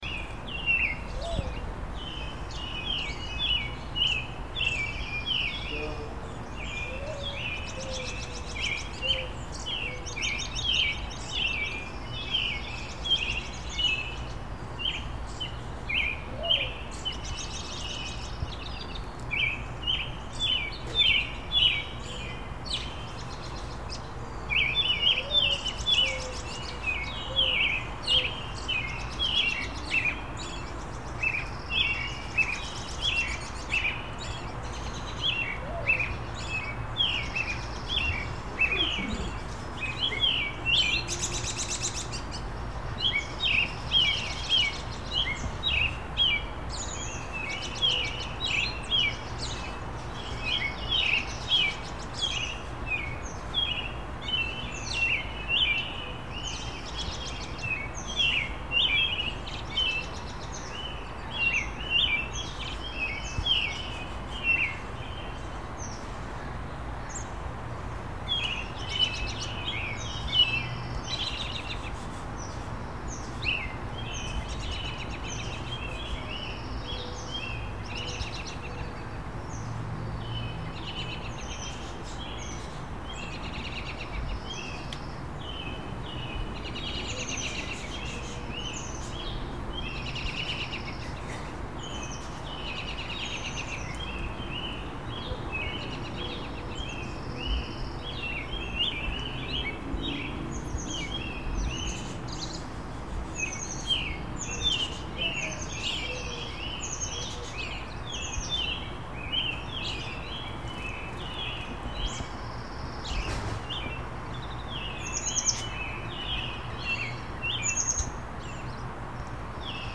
{Editor's note: Below are two recordings of bird songs:
Multiple bird songs on mic>
multiple bird songs on mic.mp3